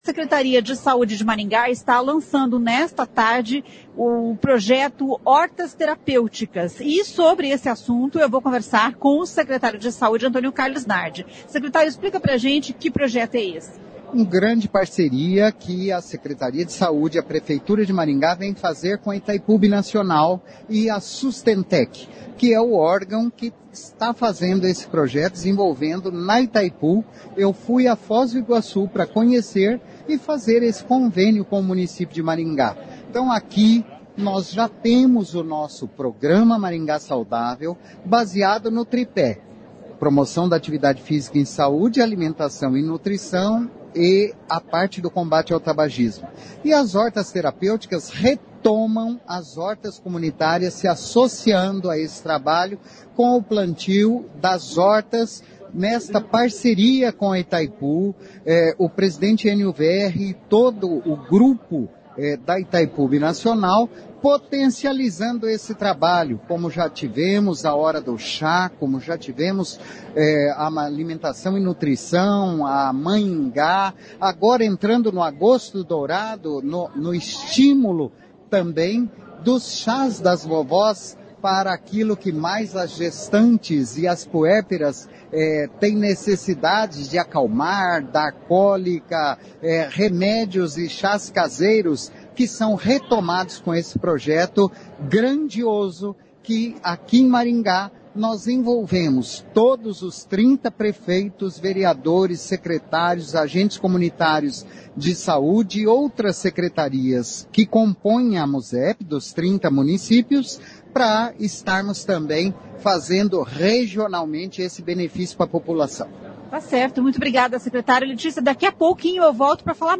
O presidente da Itaipu Binacional Ênio Verri explica como é o projeto Hortas Terapêuticas.